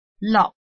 臺灣客語拼音學習網-客語聽讀拼-南四縣腔-入聲韻
拼音查詢：【南四縣腔】log ~請點選不同聲調拼音聽聽看!(例字漢字部分屬參考性質)